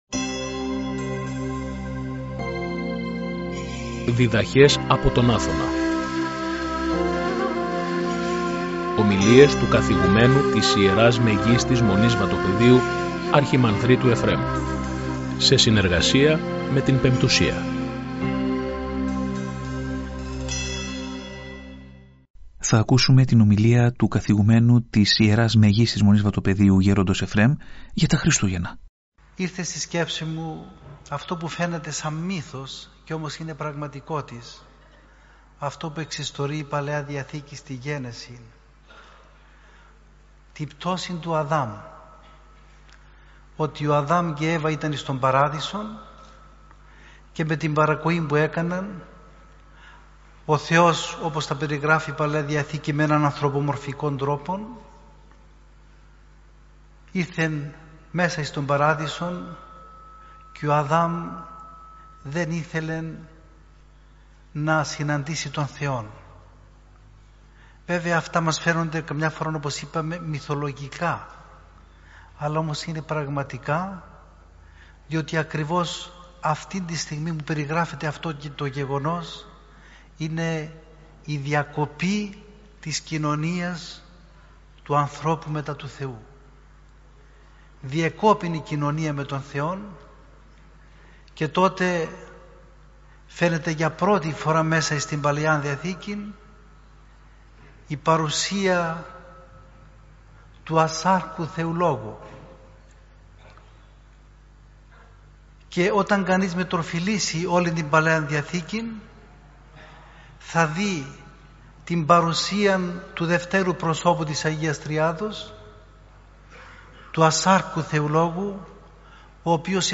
Ομιλία